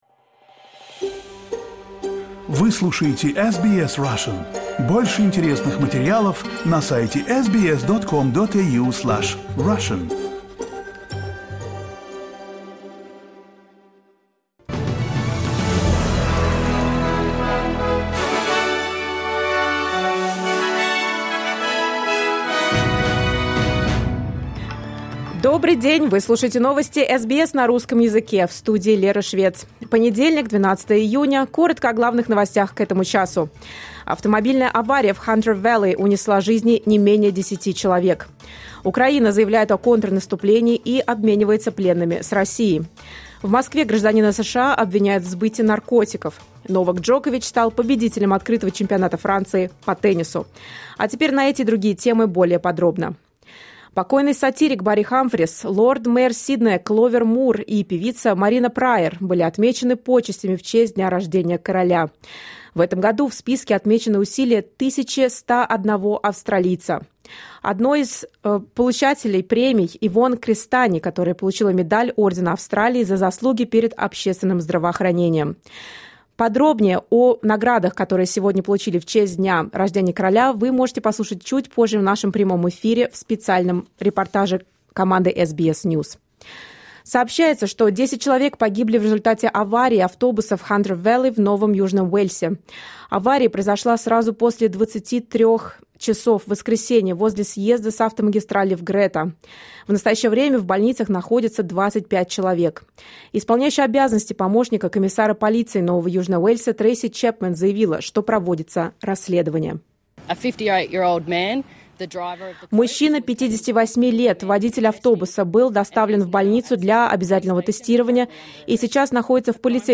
Listen to the latest news headlines in Australia from SBS Russian